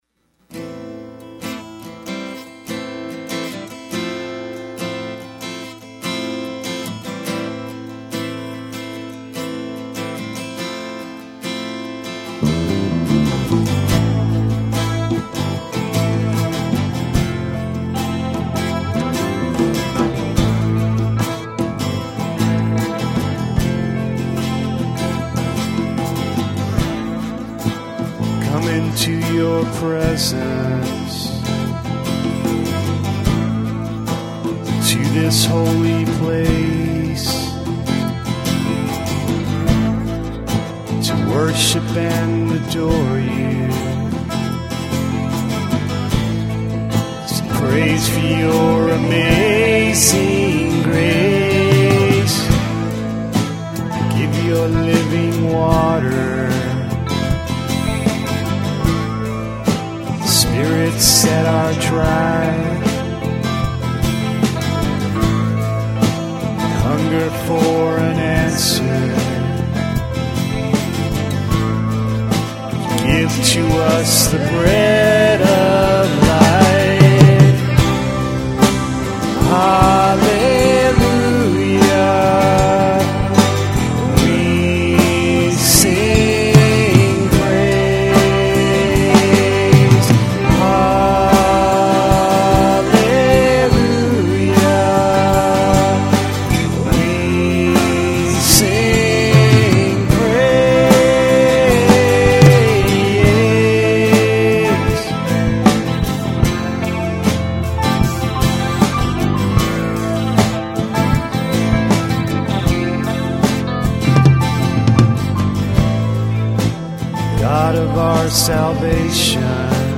Vocals / Acoustic Guitar
Bass
Electric Guitar
Drums
Piano
Flute